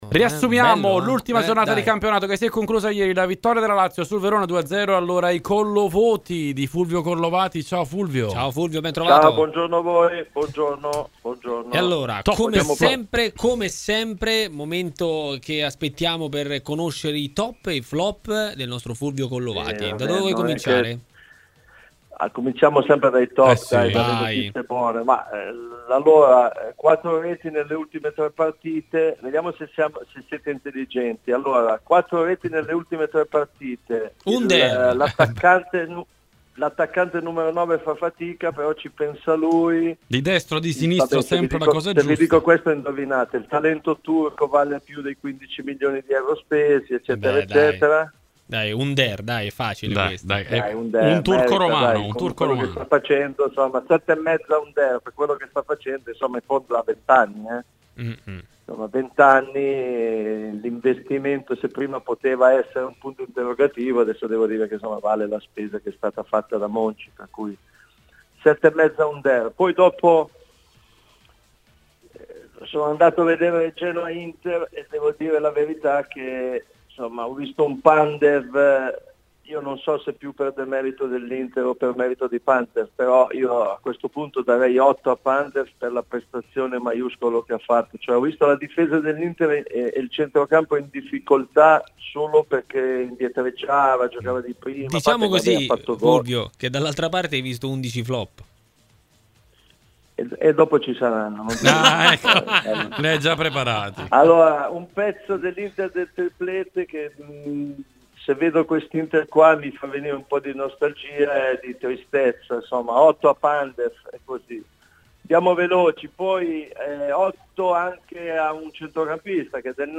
Fulvio Collovati intervistato